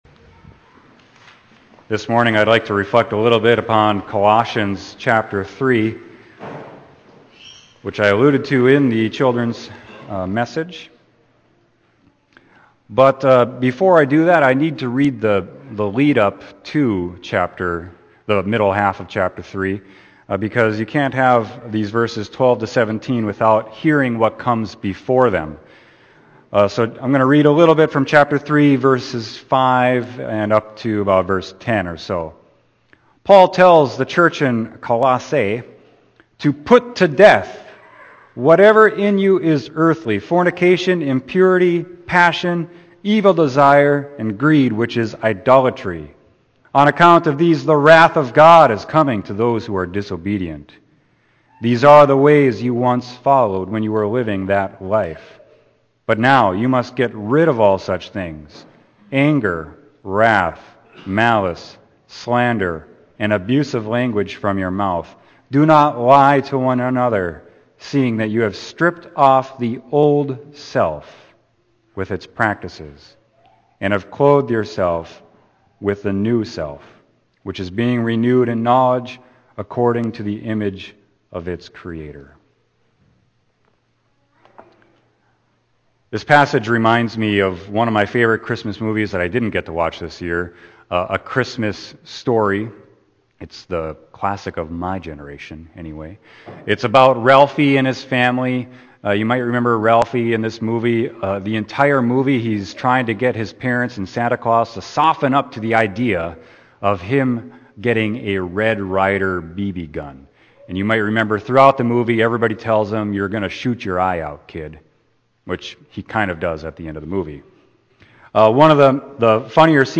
Sermon: Colossians 3.12-17